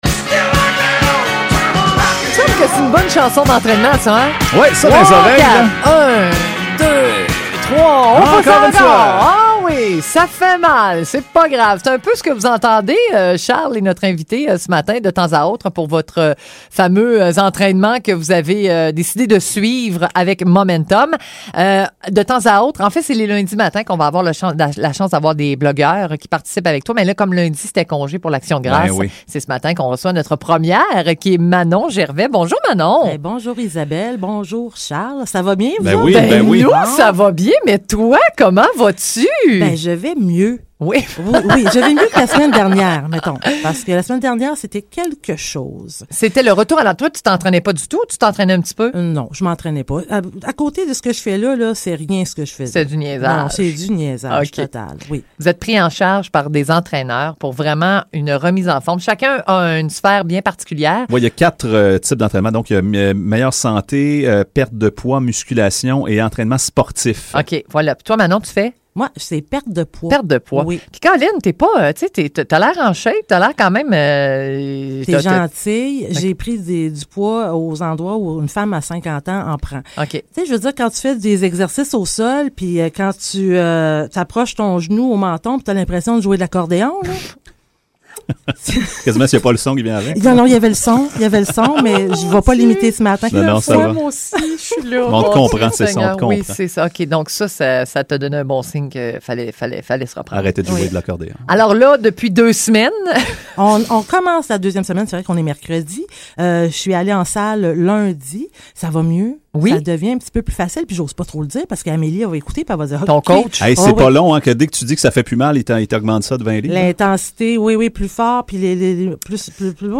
Entrevue radio